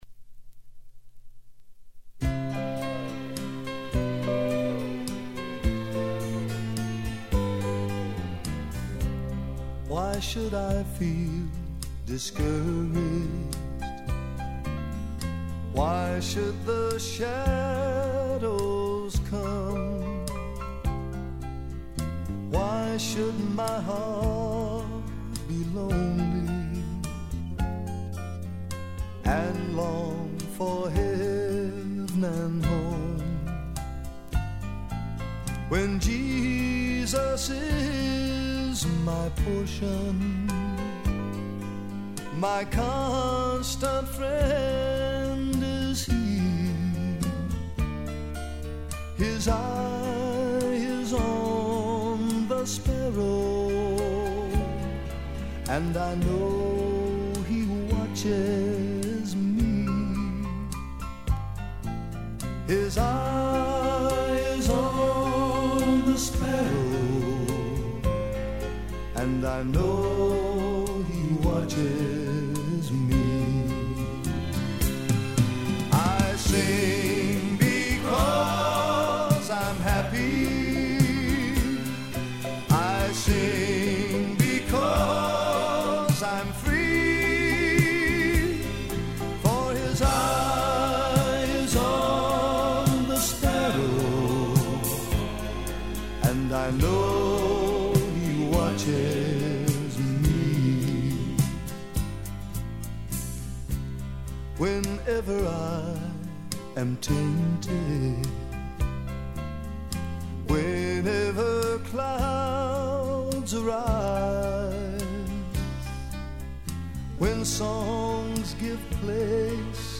Estrofe não cantada aqui :